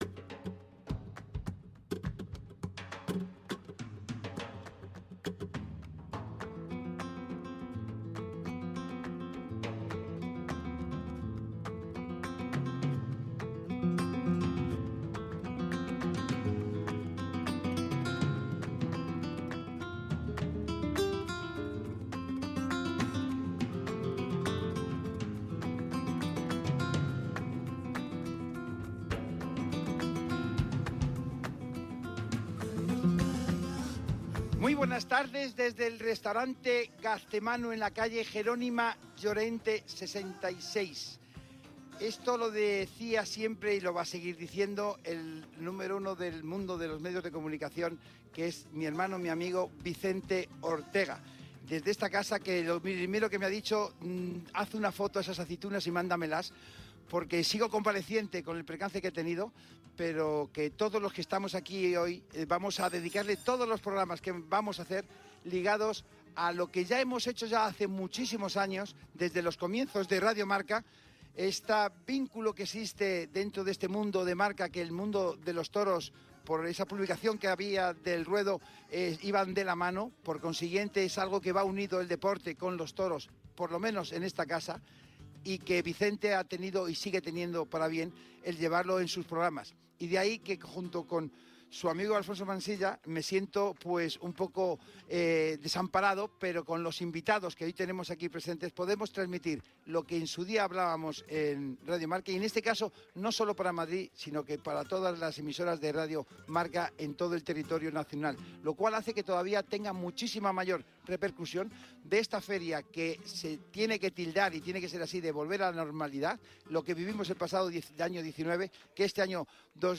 Una tertulia que una vez sienta cátedra en el mundo del toreo y que puedes volver a disfrutar aquí en directo.